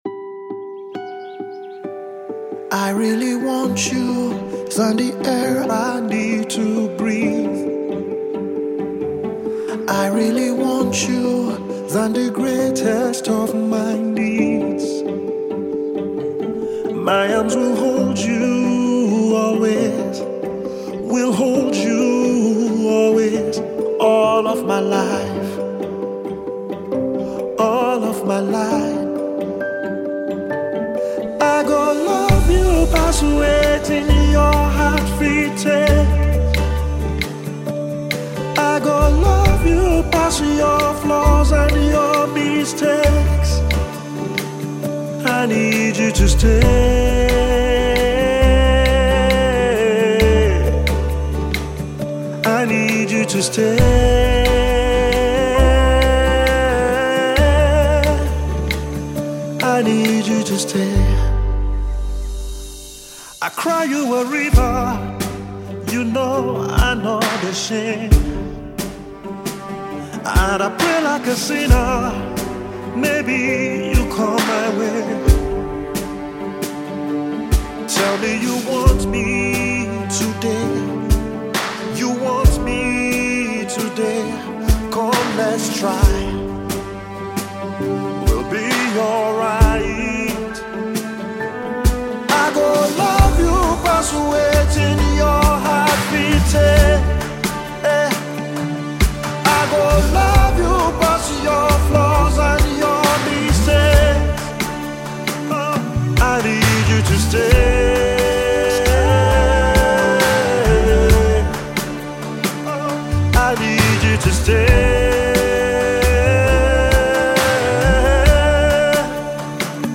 powerful love song